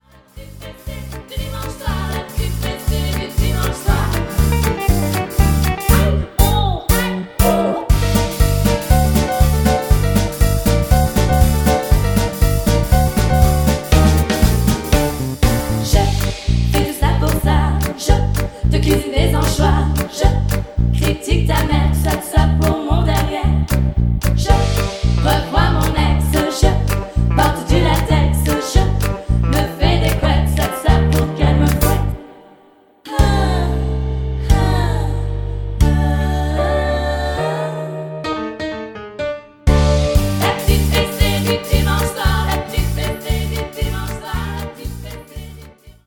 avec choeurs